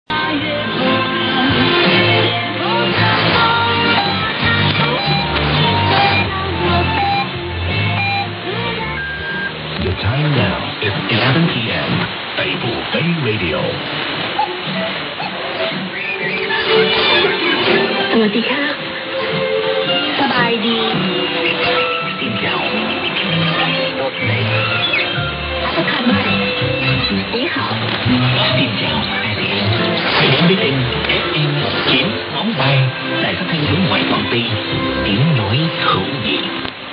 ・このＨＰに載ってい音声(ＩＳとＩＤ等)は、当家(POST No. 488-xxxx)愛知県尾張旭市で受信した物です。
ST: signature tune/jingle